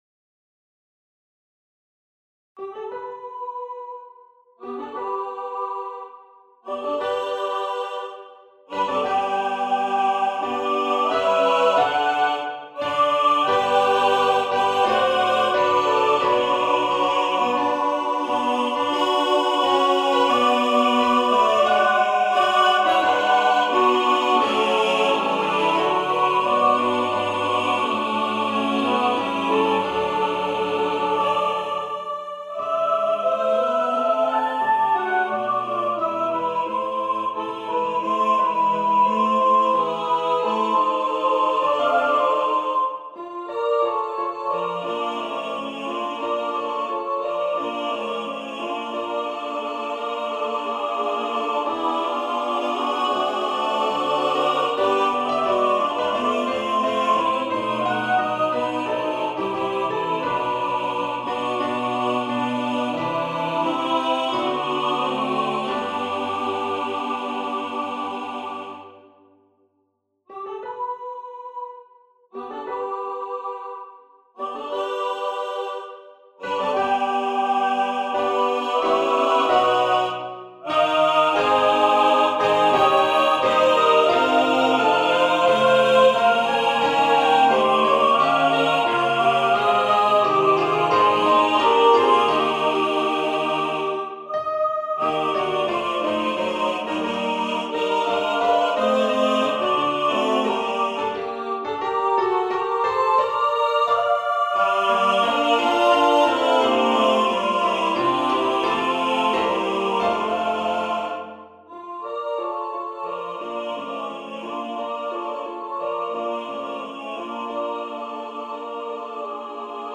Voices: S1,S2,A1,A2,T1,T2,B1,B2 Instrumentation: a cappella
NotePerformer 5.1 mp3 Download/Play Audio